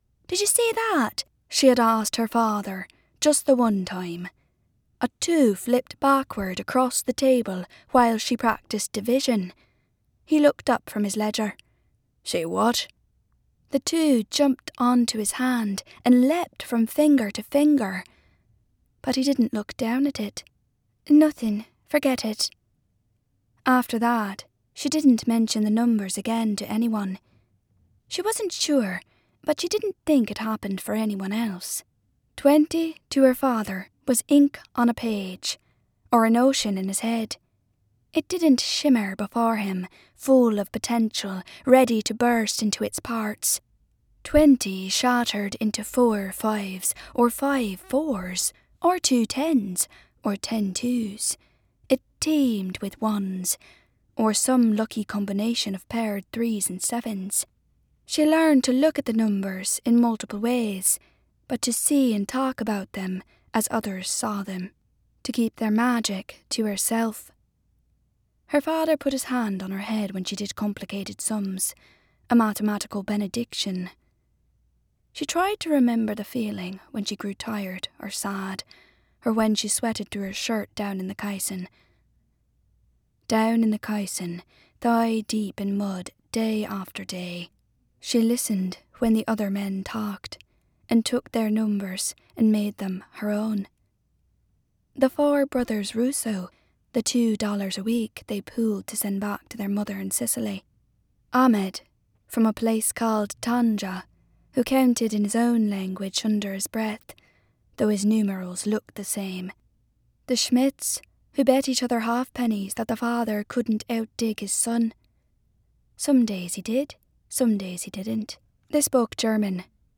Teenager, Adult, Young Adult
Has Own Studio
Irish